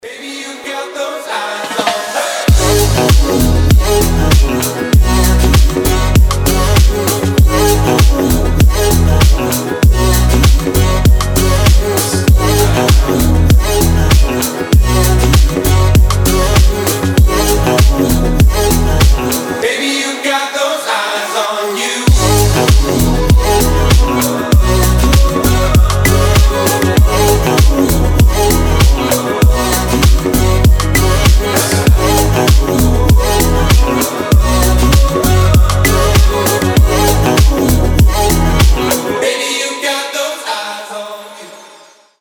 • Качество: 320, Stereo
мужской вокал
deep house
tropical house
теплые
Chill